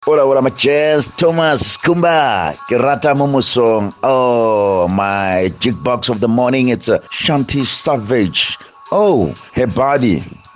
Here are some of the views from Kaya 959 listeners: